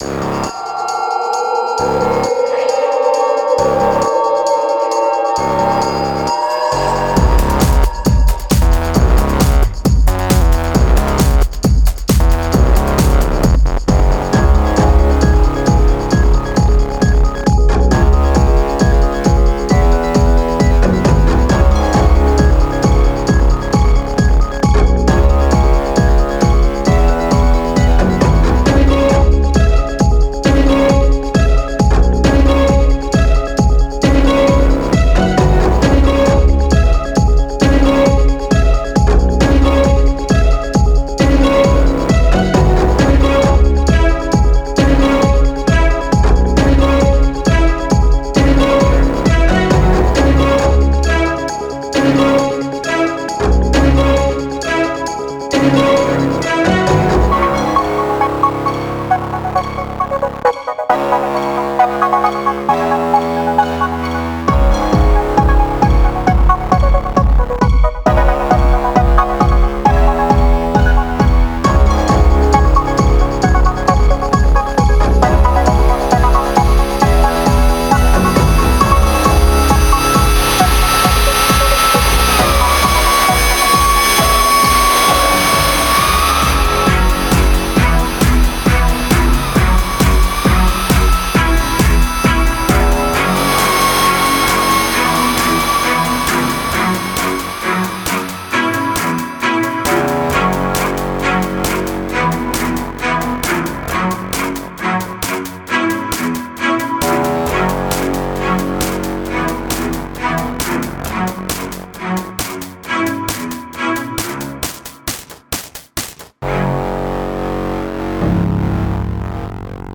Industrial-Classical Fusion